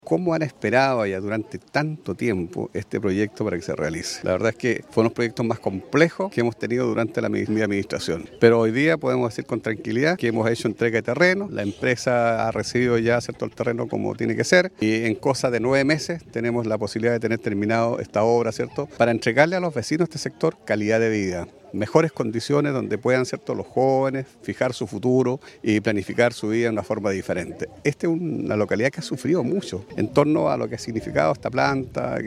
Al respecto, el edil de la comuna, Jaime Bertín comentó que “fue uno de los proyectos más complejos dentro de mi administración”.